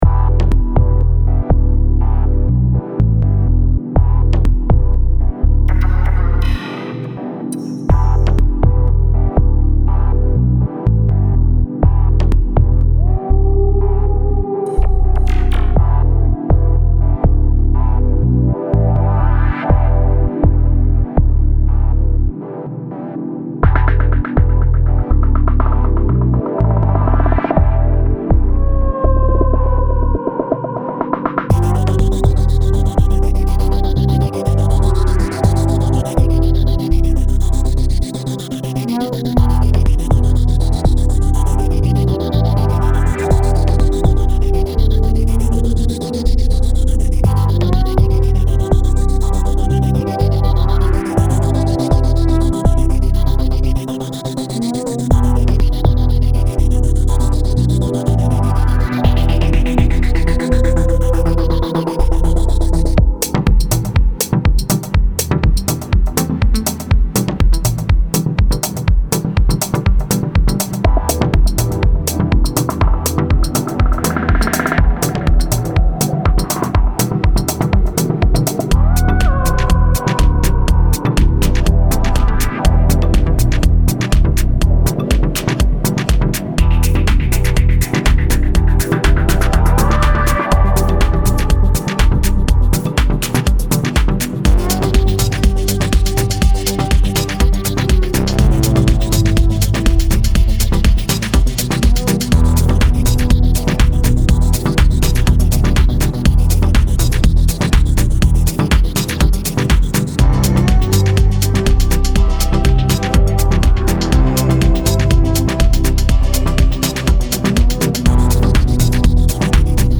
Recently made with PS-20...